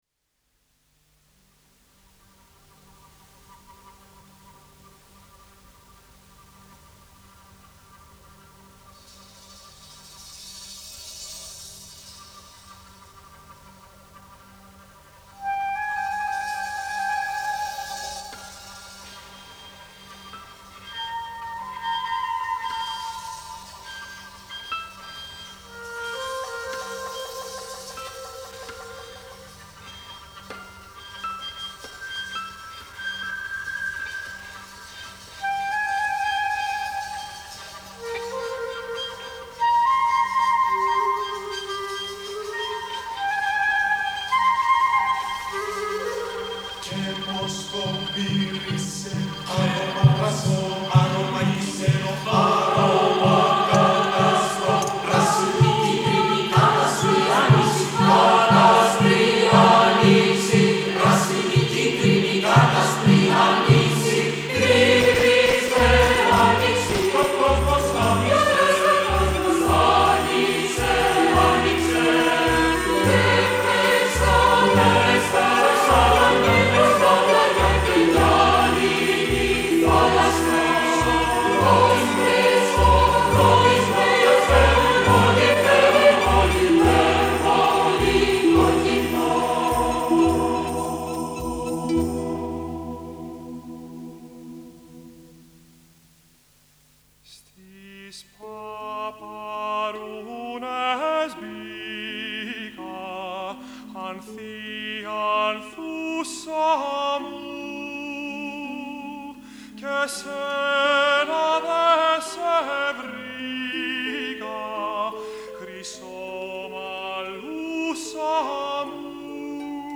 radio opera for children. 1979